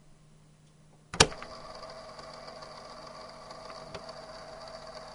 tape_start.wav